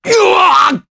Crow-Vox_Damage_jp_03.wav